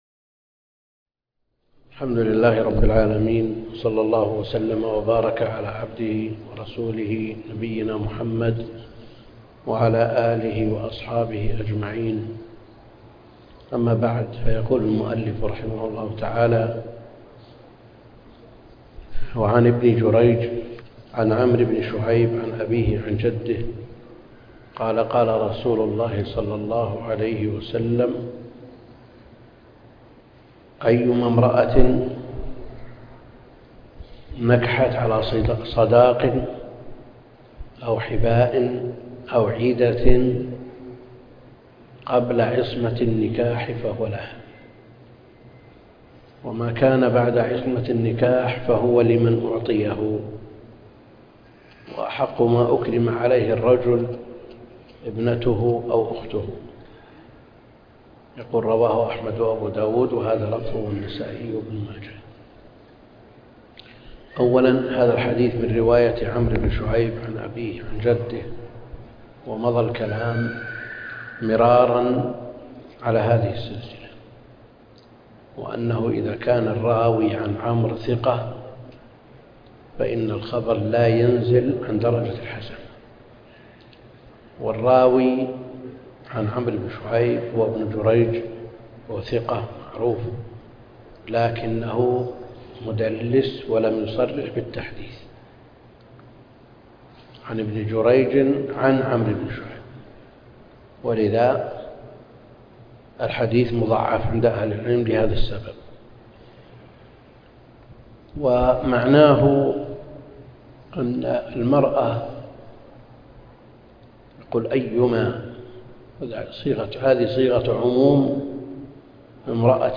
الدرس (2) كتاب الصداق من المحرر في الحديث - الدكتور عبد الكريم الخضير